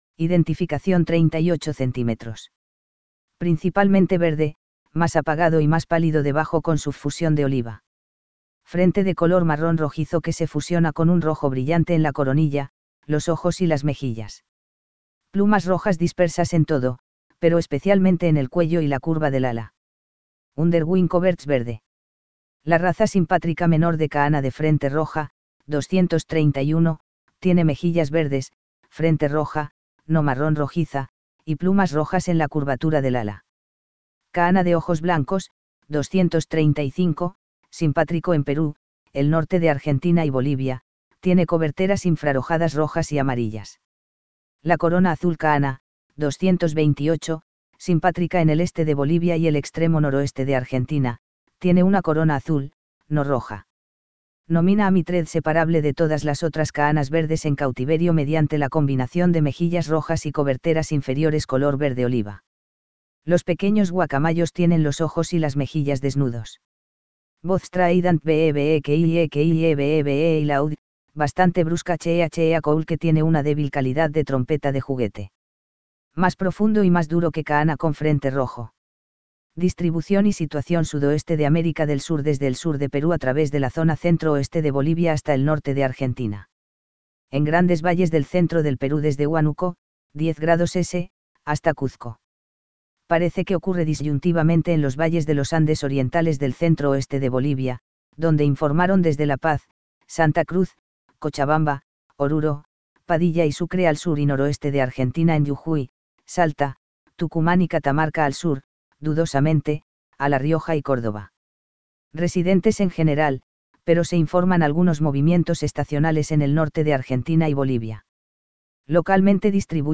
VOZ Strident weee weee queiiee queiiee weee weee y loud, bastante brusca cheeah cheeah call que tiene una débil calidad de trompeta de juguete. Más profundo y más duro que Conure con frente rojo.